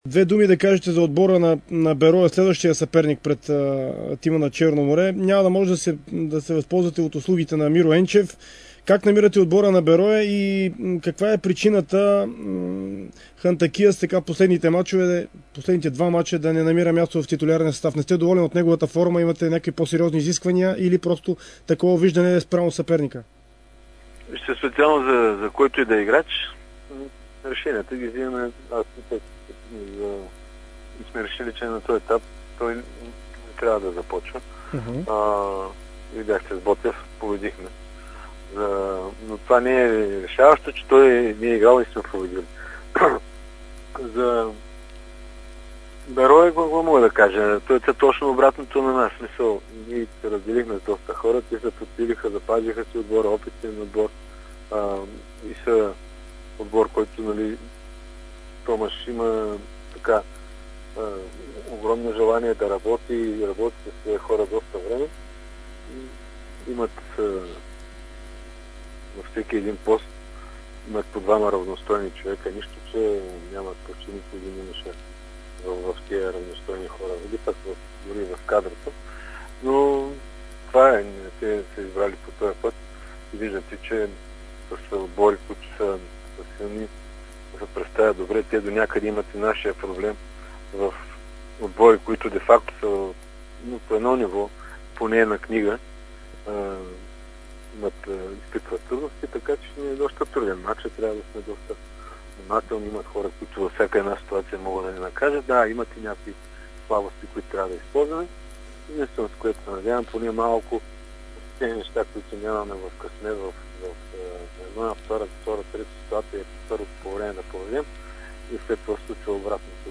Искам малко повече късмет и агресия срещу Берое", коментира в интервю за Дарик радио и dsport треньорът на Черно море Илиан Илиев.